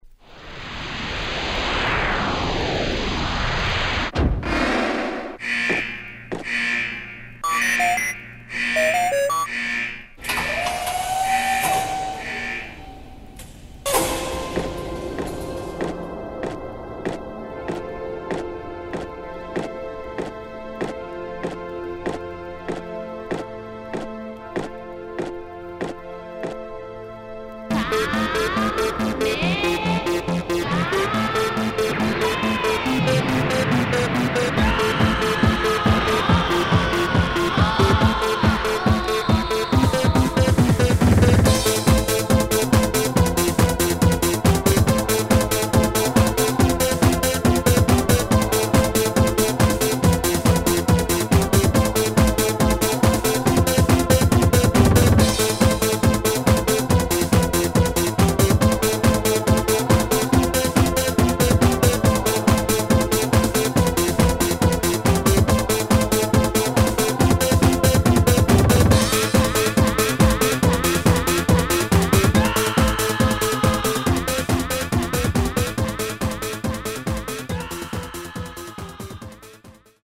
bakalao